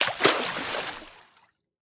Minecraft Version Minecraft Version 25w18a Latest Release | Latest Snapshot 25w18a / assets / minecraft / sounds / mob / dolphin / splash2.ogg Compare With Compare With Latest Release | Latest Snapshot
splash2.ogg